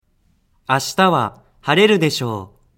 次に、元の言葉で発音します。
滑舌母音練習２.mp3